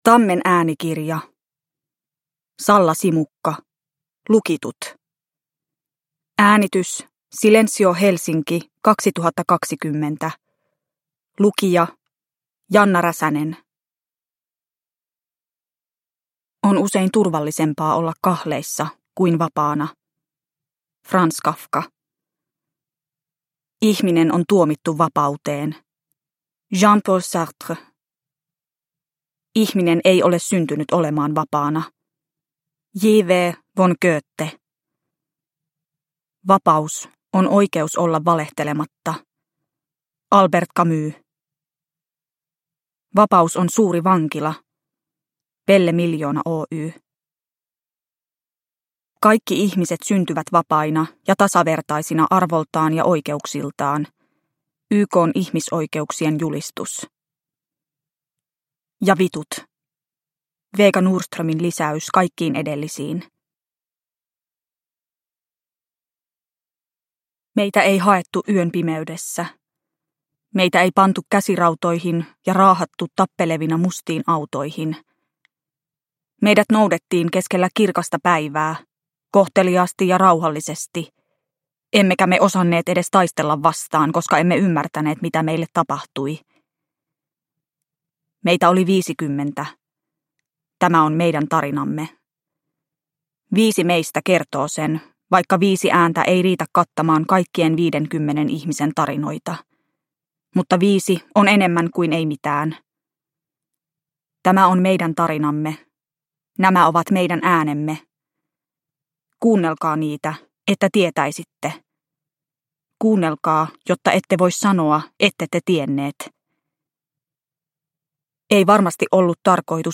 Lukitut – Ljudbok